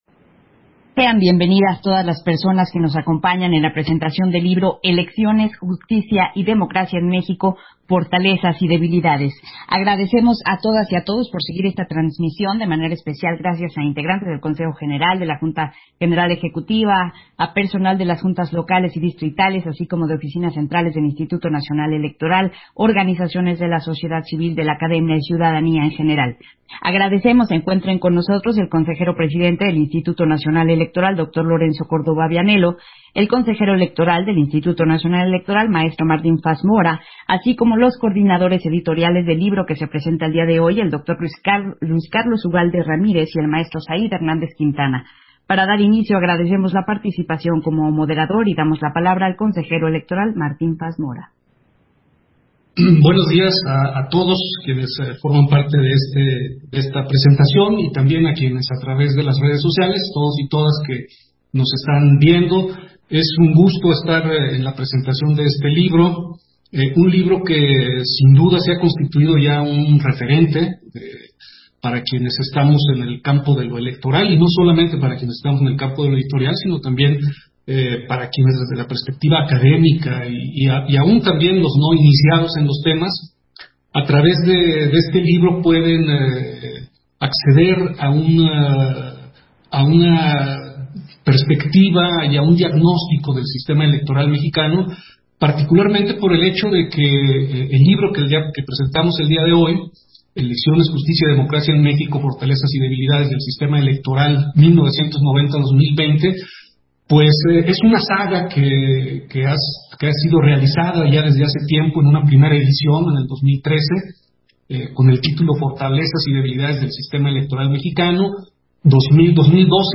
190821_AUDIO_PRESENTACIÓN-DE-LIBRO-ELECCIONES-JUSTICIA-Y-DEMOCRACIA-EN-MÉXICO - Central Electoral